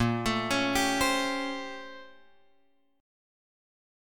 A#mM13 chord {6 x 7 6 8 8} chord